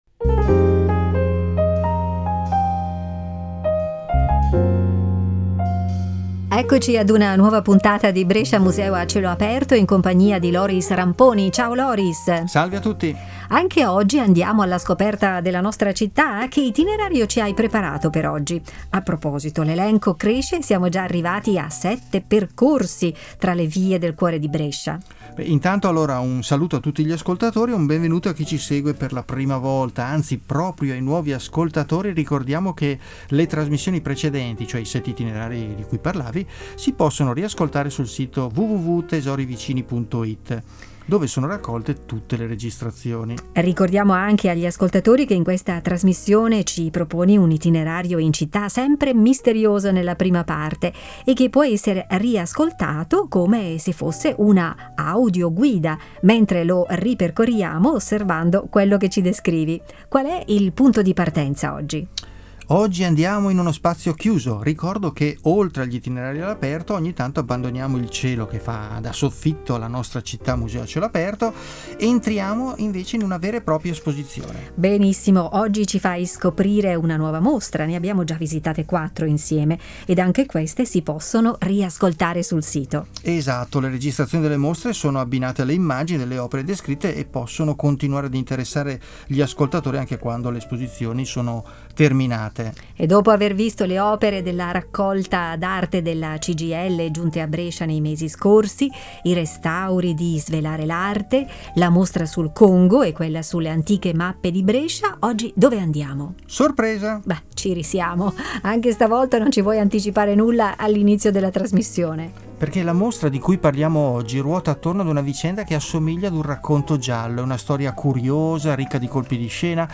audio-guida e itinerari per passeggiare tra i musei
Successivamente puoi guardare il p. point che ti propone i quadri descritti nel nostro itineriario, mentre ascolti la trasmissione.